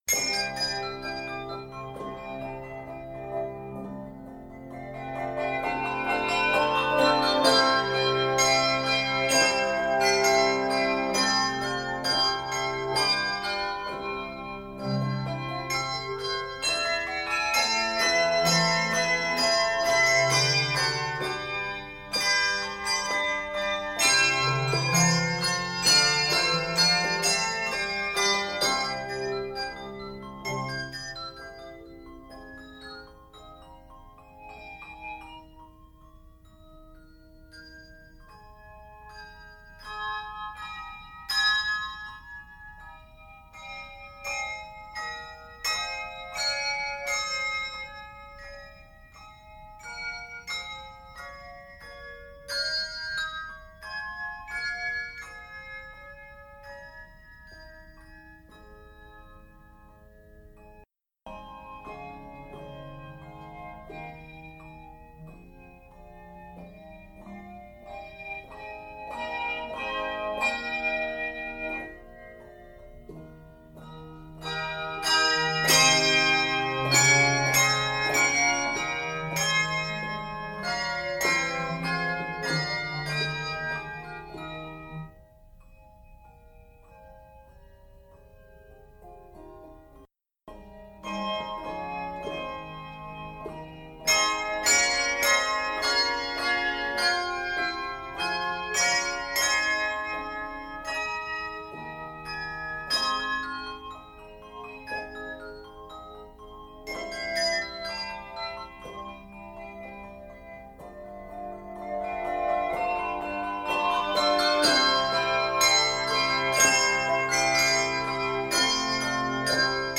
Review: Christmas ringing at its finest!